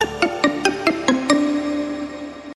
ambient-sound-effect_25134.mp3